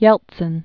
(yĕltsĭn), Boris Nikolayevich 1931-2007.